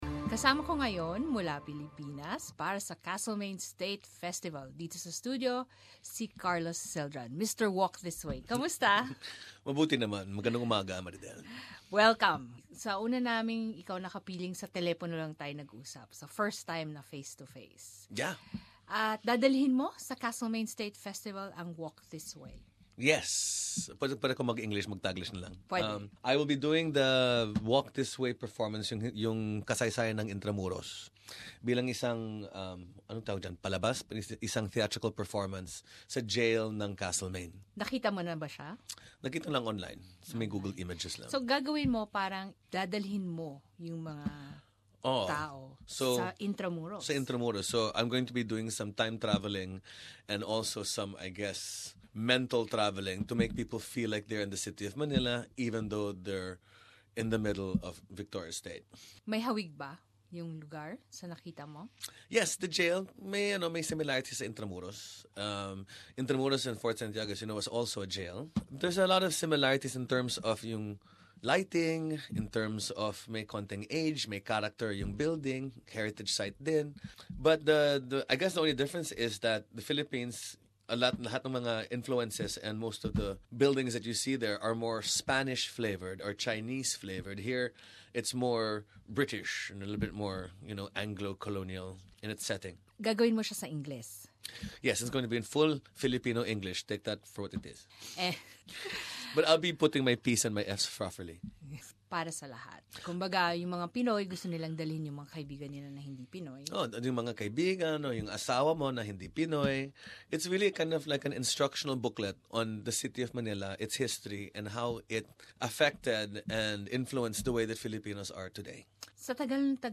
Listen in to his 2017 interview during his visit to SBS Radio.